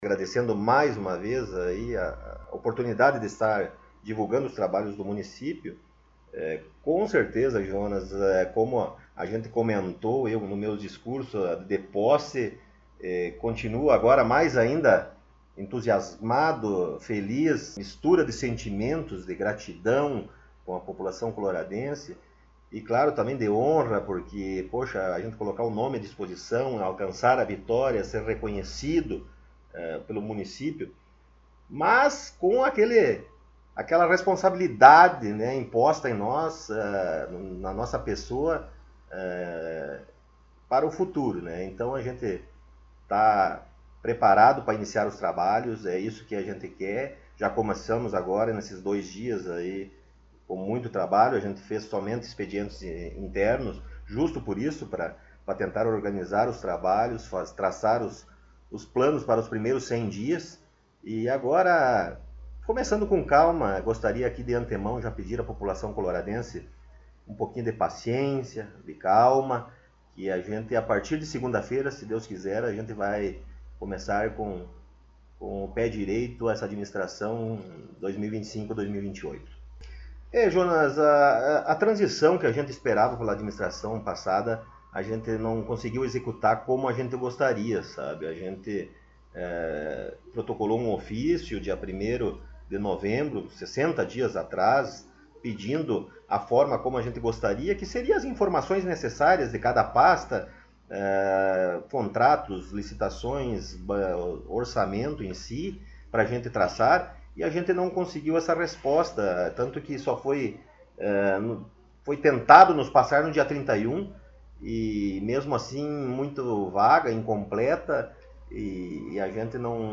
Prefeito Municipal concedeu entrevista
Na última sexta-feira estivemos no gabinete do Prefeito Municipal, Sr. Rodrigo Sartori, para entrevistarmos e obtermos as primeiras informações da atual Administração Municipal. Na oportunidade o mesmo falou sobre a honraria em exercer o cargo máximo de nosso município, sobre os atendimentos da semana, trabalhos e futuros projetos.